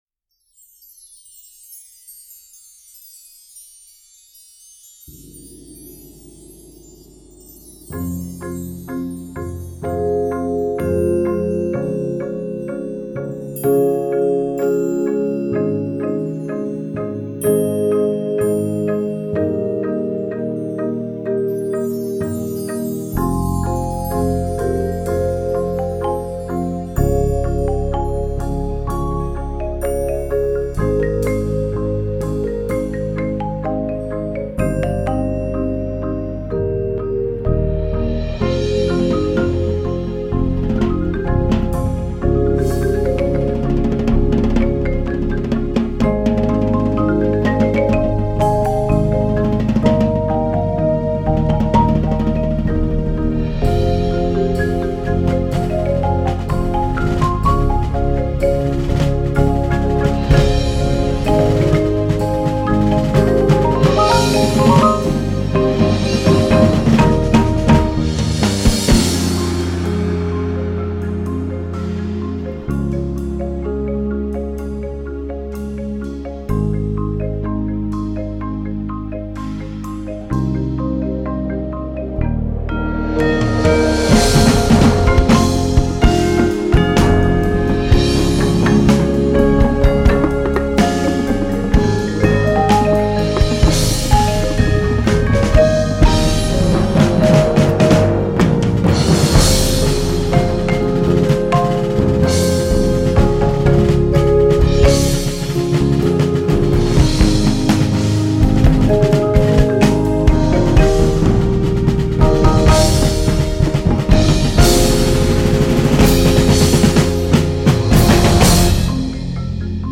Voicing: 17-20 Percussion